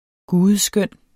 Udtale [ ˈguːðəˌ- ]